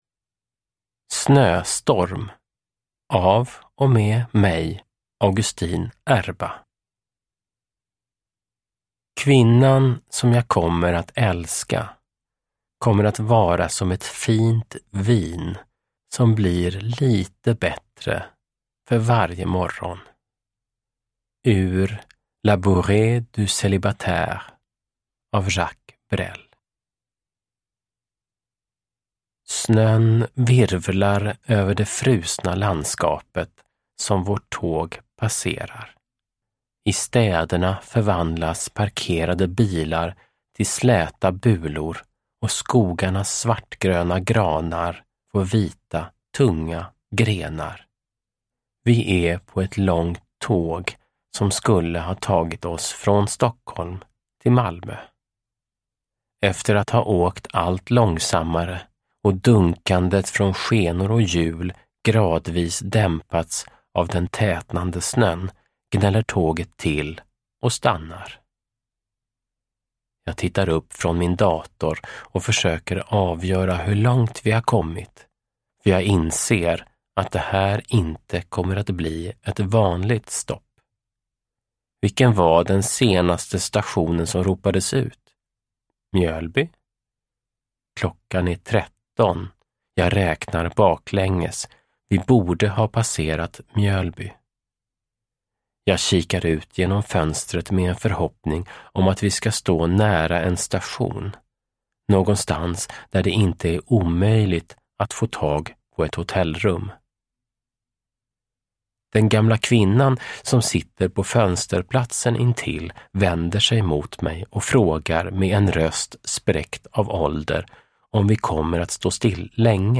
Snöstorm – Ljudbok
Uppläsare: Augustin Erba